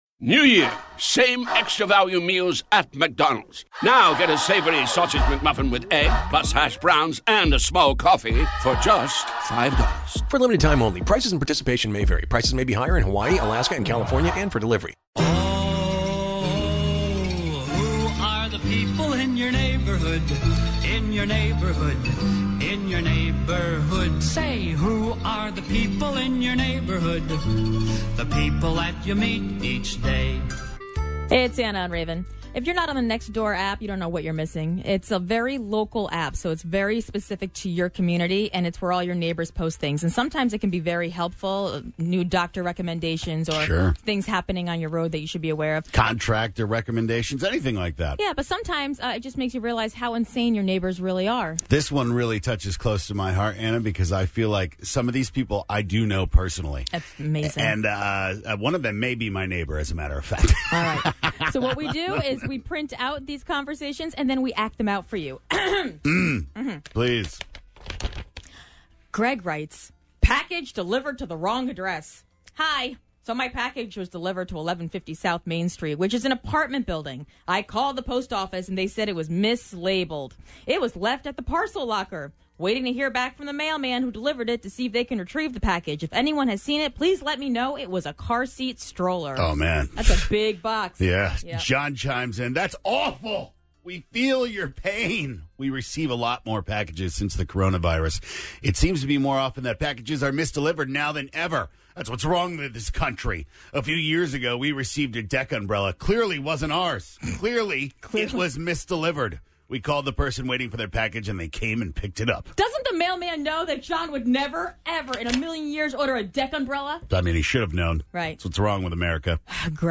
Nothing is more entertaining that acting out the conversations you read from your Next Door App!
It's theater of the mind at it's best!